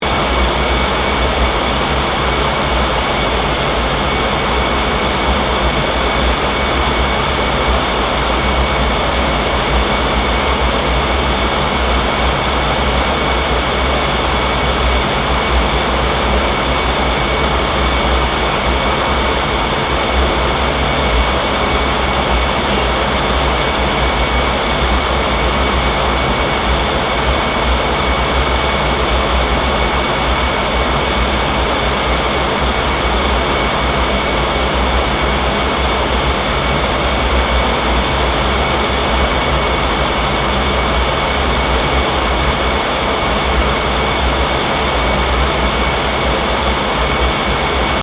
Soundscape: Paranal UT chiller
(Interior) Fan sounds are very characteristic at the telescopes’ enclosures. This sound corresponds to the air conditioning inside a Unitary Telescope (UT), an active thermal control during the day that keeps the area at 5-11°C, as this is the average exterior temperature for the beginning of the night.
ss-paranal-ut1-chiller_mono.mp3